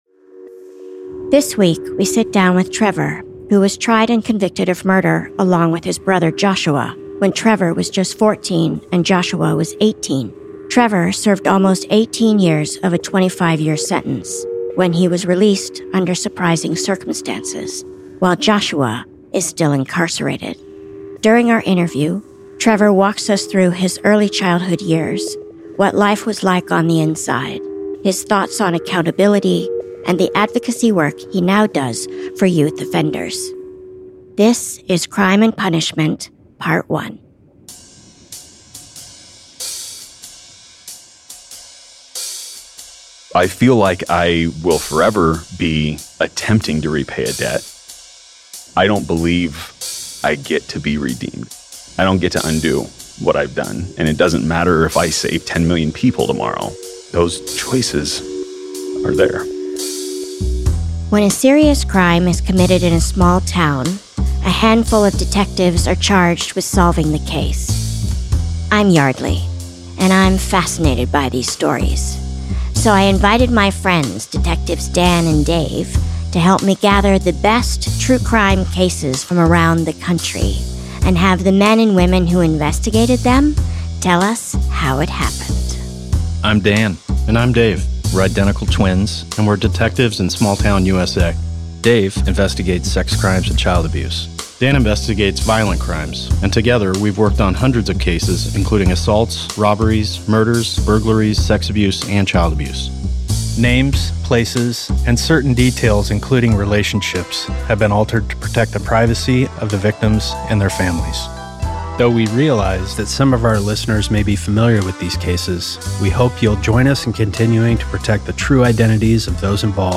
In this two-part conversation, we talk to him about the crime he committed, his co-defendant, how he spent his time in prison, and his emergence as an advocate for giving other inmates a second chance to become productive members of society.